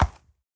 horse